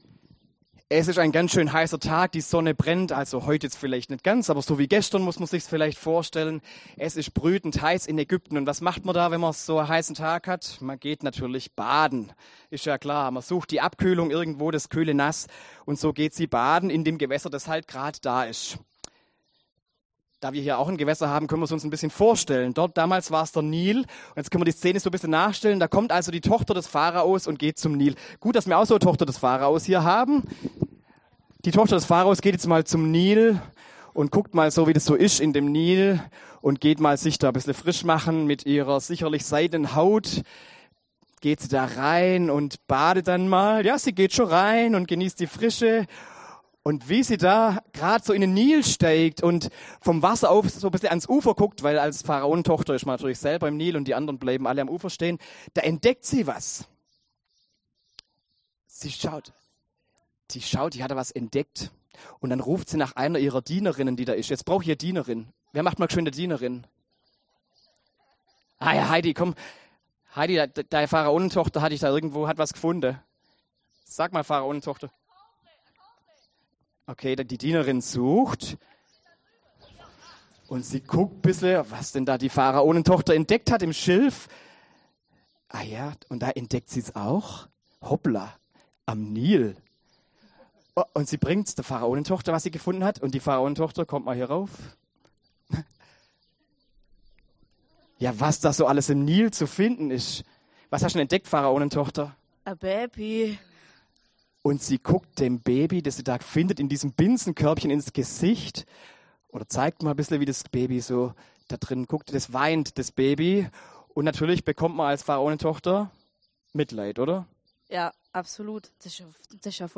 Predigt am Hülenhock